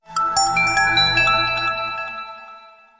MagicBell.mp3